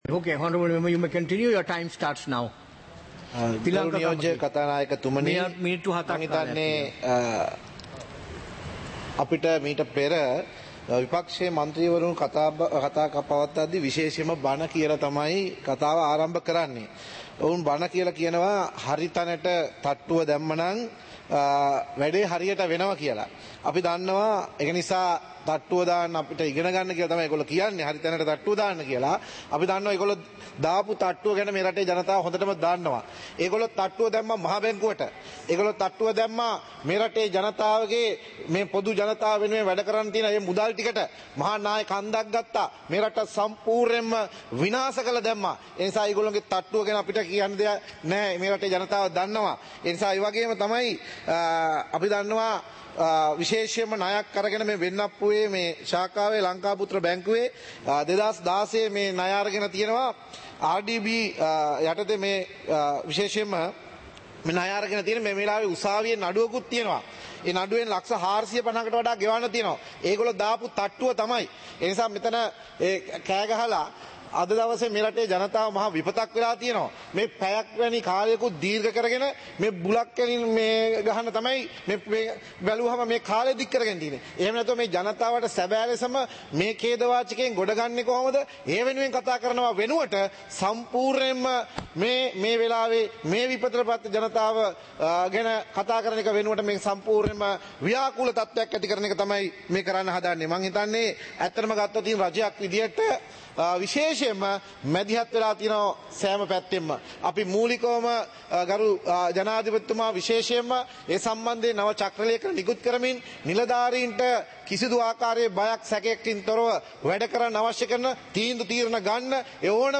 சபை நடவடிக்கைமுறை (2025-12-05)